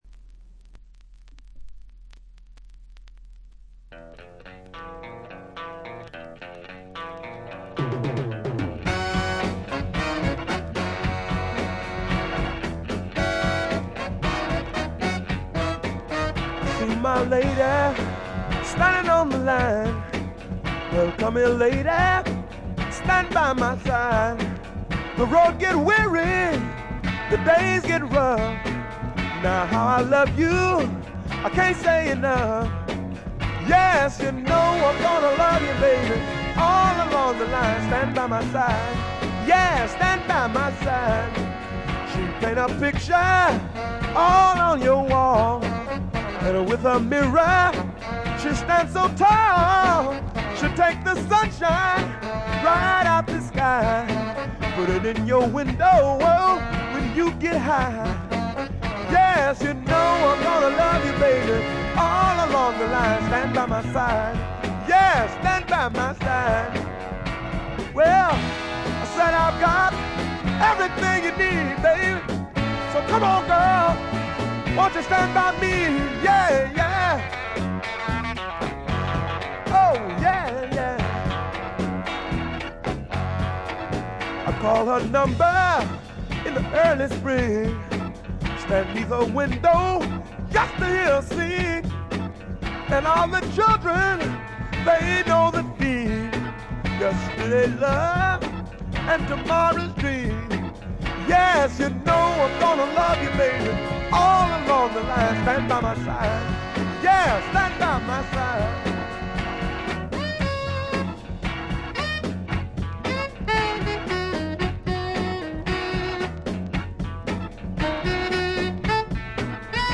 部分試聴ですが、軽微なチリプチ、バックグラウンドノイズ程度。
試聴曲は現品からの取り込み音源です。